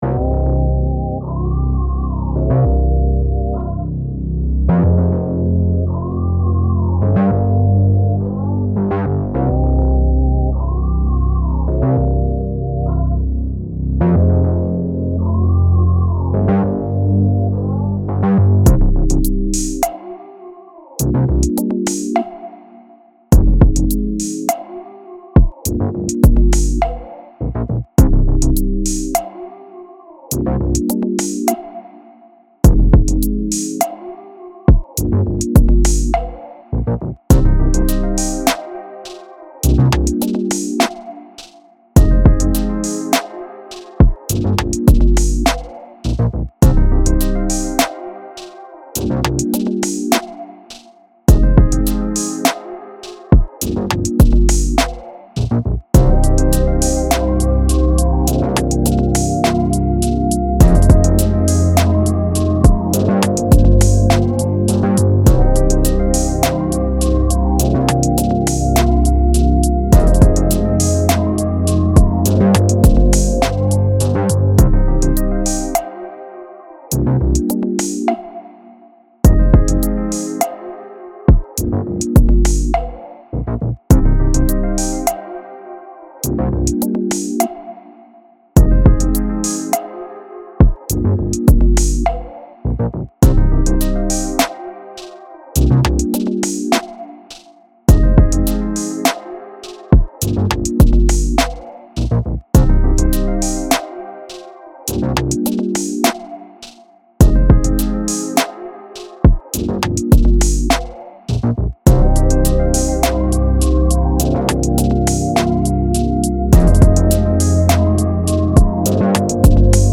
R&B
C Minor